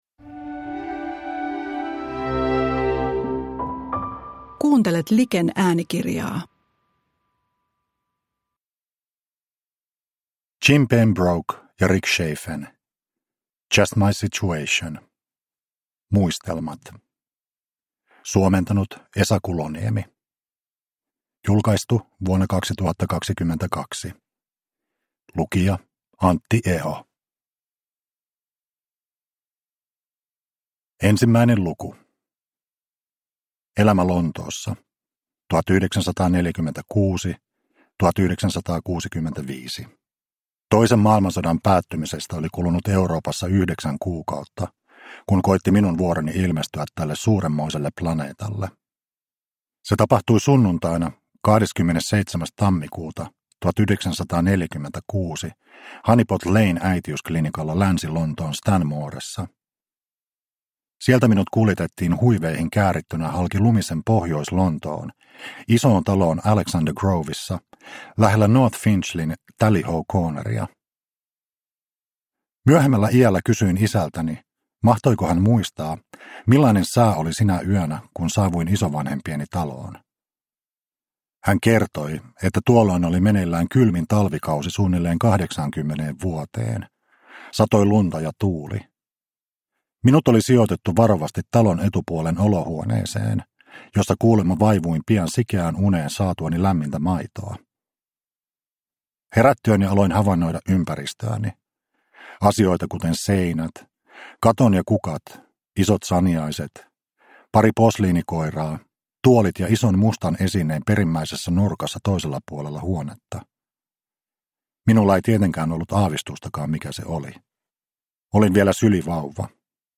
Just My Situation – Ljudbok – Laddas ner